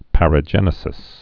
(părə-jĕnĭ-sĭs) also par·a·ge·ne·sia (-jə-nēzhə)